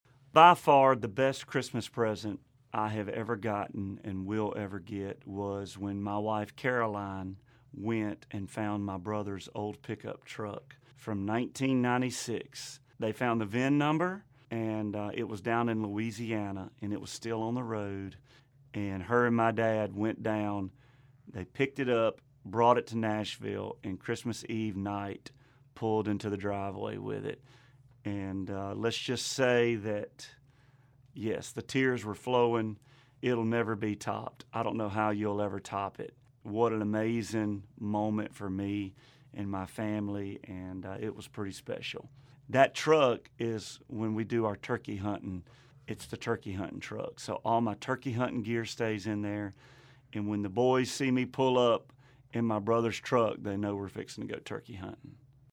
Audio / Luke Bryan recalls his most meaningful Christmas present.